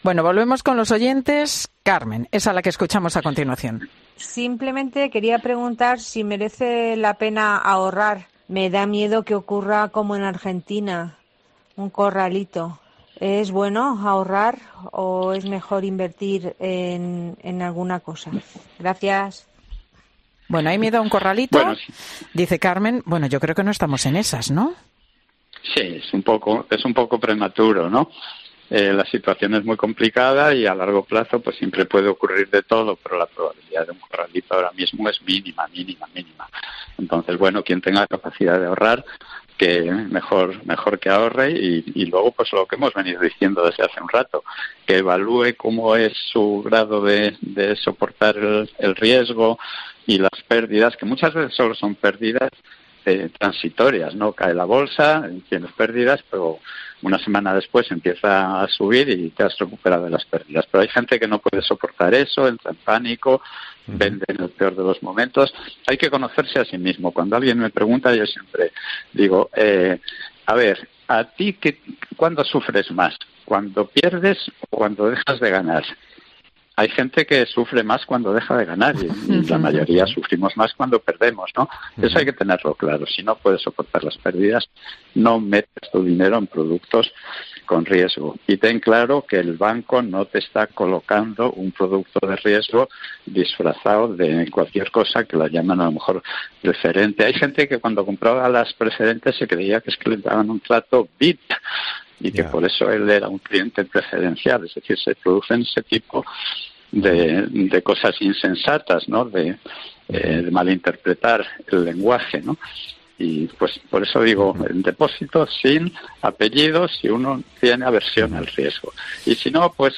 Un experto habla sobre las posibilidades y lo que hacer en caso de corralito financiero